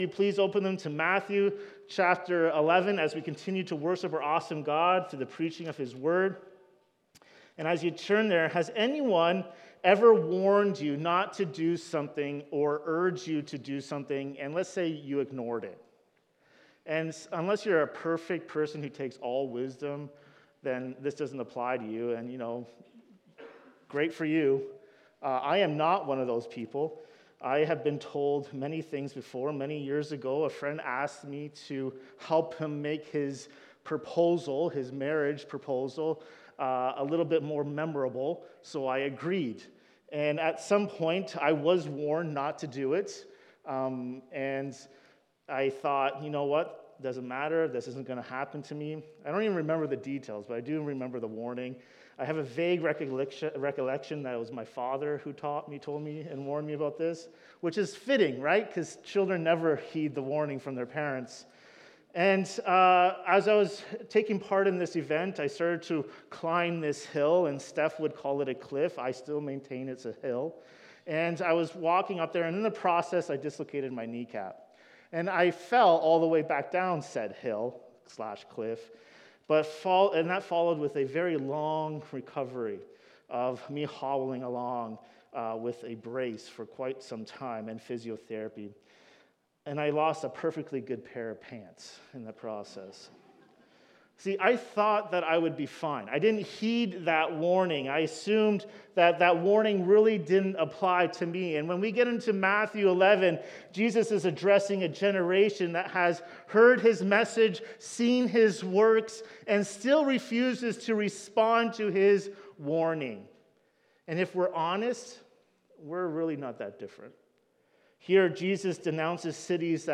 In this sermon on Matthew 11:20–24, we explore the “merciful woes” Jesus pronounces over cities that had seen His miracles and heard His message, yet refused to repent.